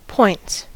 points: Wikimedia Commons US English Pronunciations
En-us-points.WAV